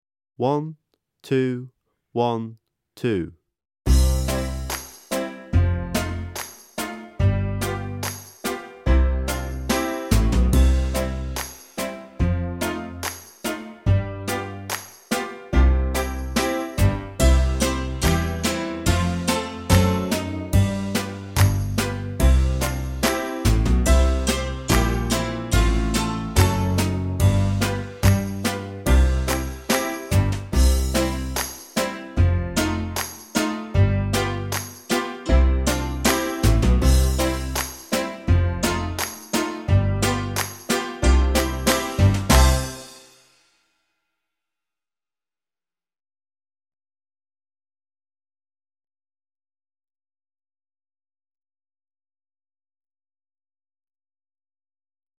56. Little Brown Jug (Backing Track)